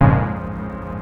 HOUSE 7-L.wav